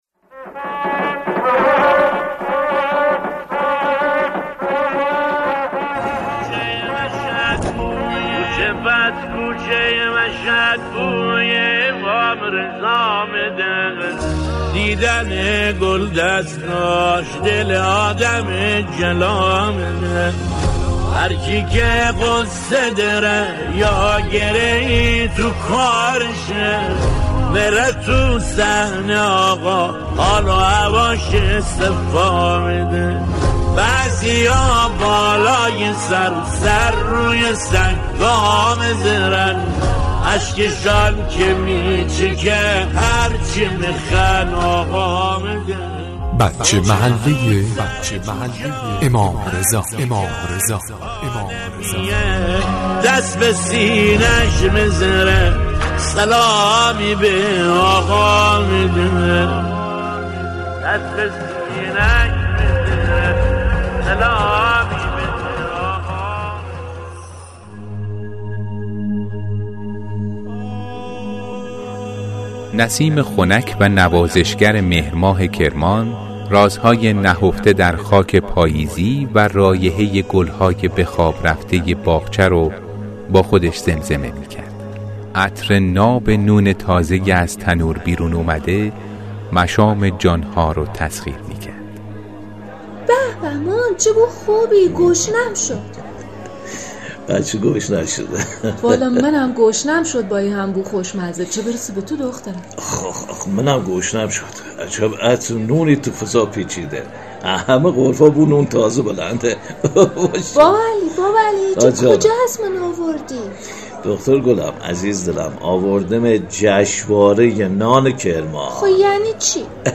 برچسب ها: رادیو رادیو رضوی کتاب صوتی